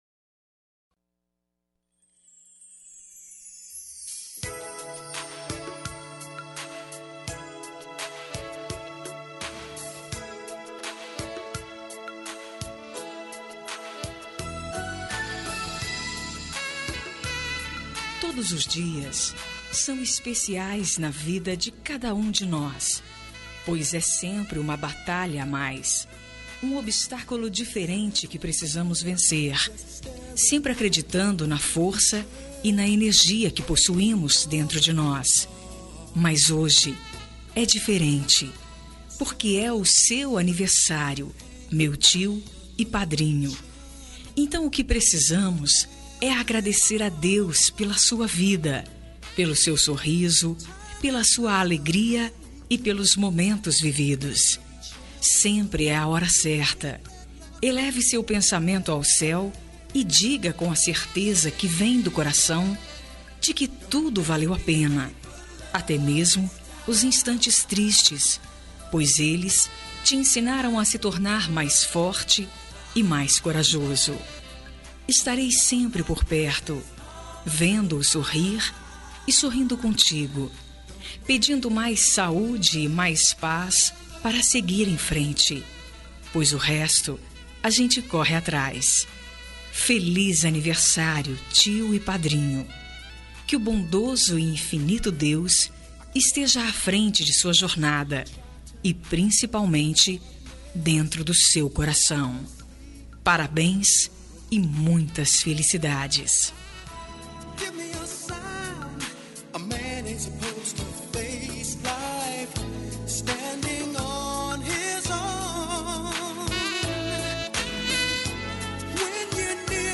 Aniversário de Tio – Voz Feminina – Cód: 920 – Tio/ Padrinho